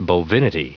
Prononciation du mot bovinity en anglais (fichier audio)
bovinity.wav